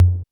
LTOMS808 4.wav